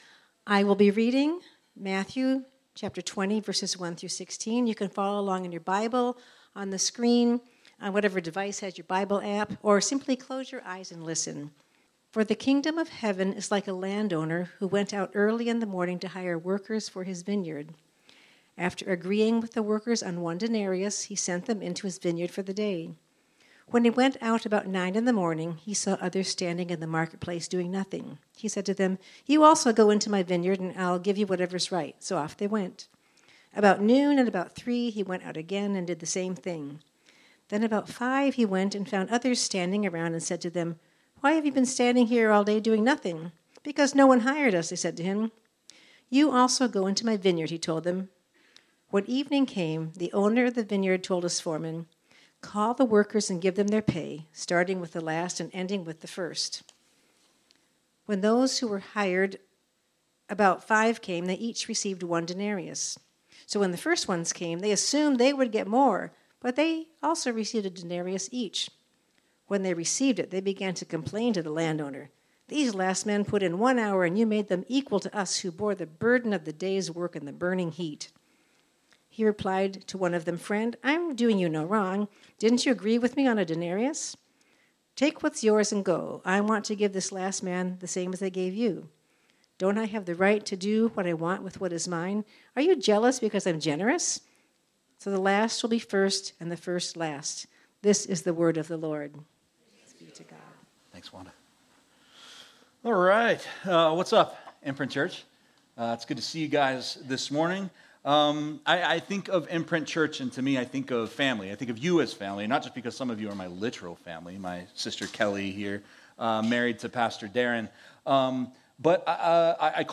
This sermon was originally preached on Sunday, September 15, 2024.